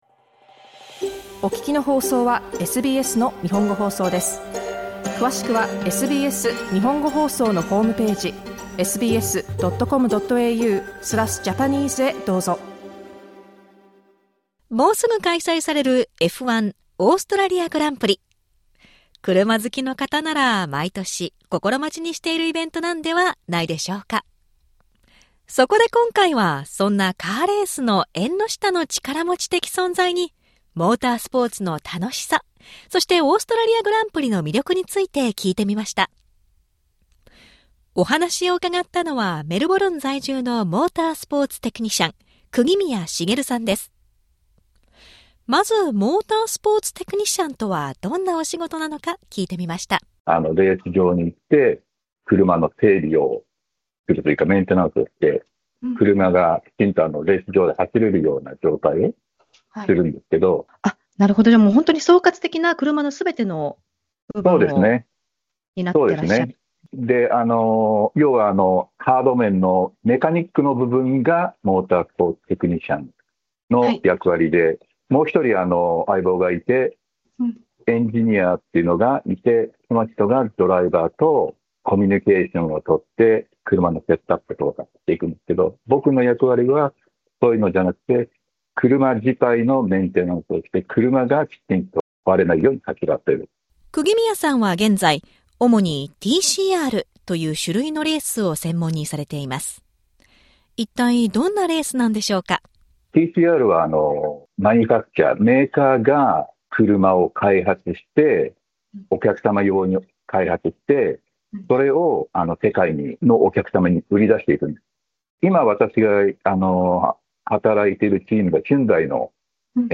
Listen to the whole interview on our podcast.